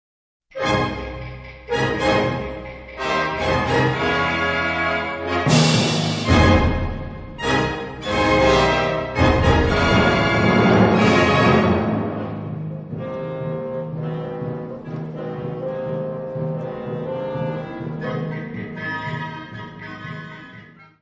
Área:  Música Clássica
Giocoso.